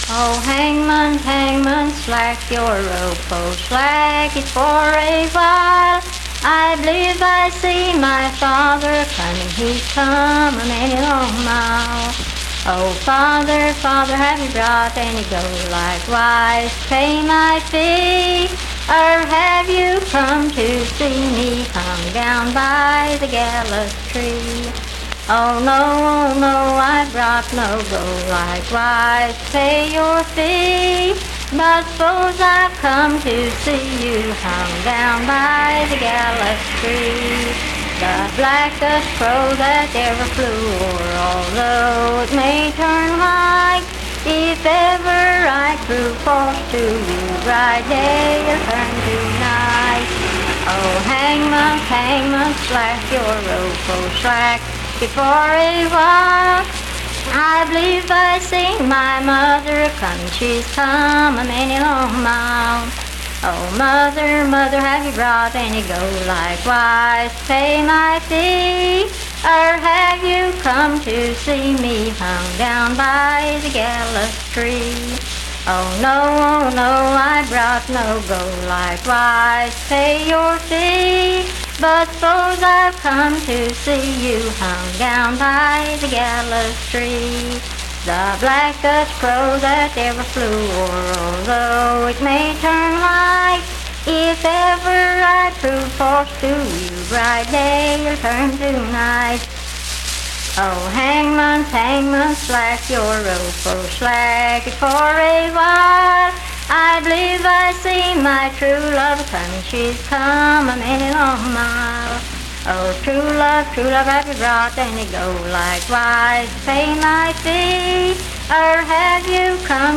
Unaccompanied vocal music
Voice (sung)
Roane County (W. Va.), Spencer (W. Va.)